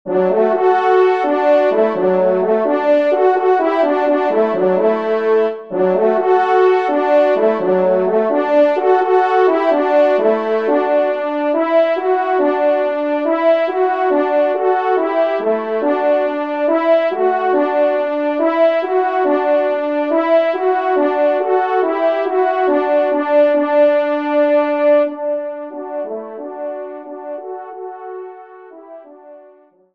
Genre :  Musique Religieuse pour Trompes ou Cors en Ré
2e Trompe